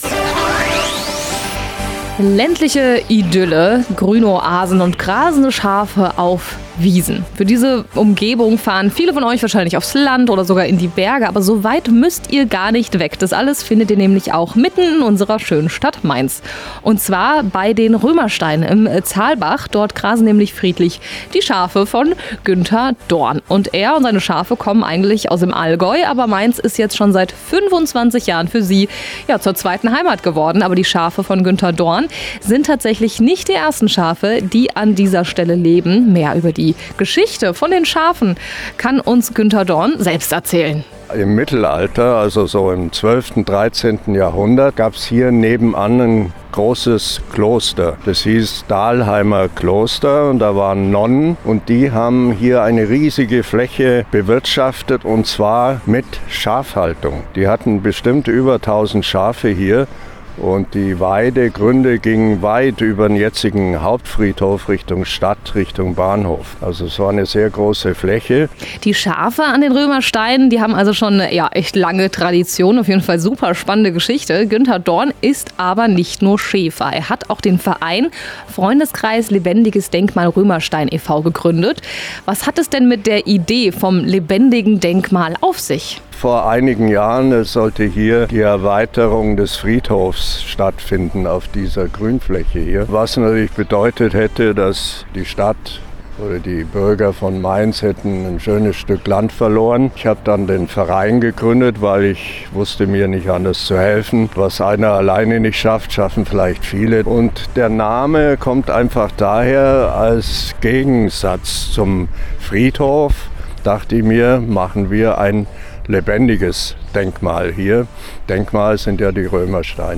Die Schafe im 📻 Radio bei Antenne Mainz (hört rein